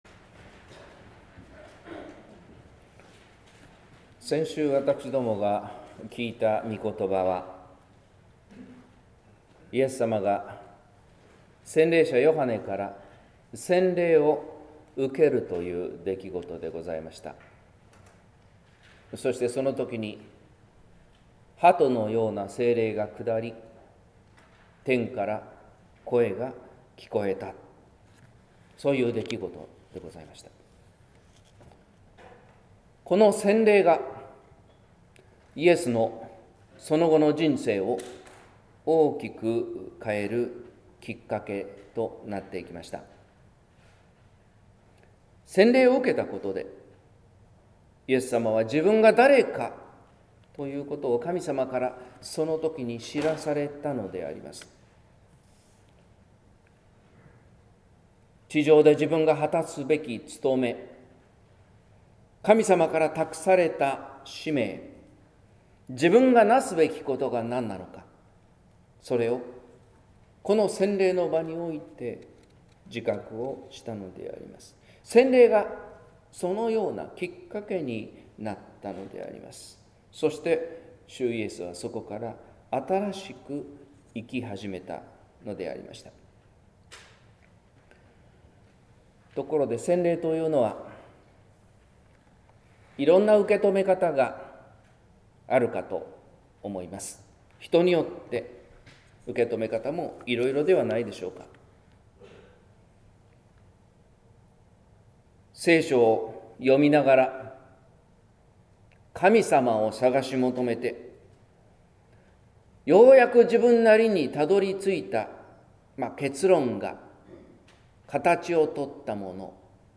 説教「なんと身近な神の国」（音声版） | 日本福音ルーテル市ヶ谷教会
顕現節第３主日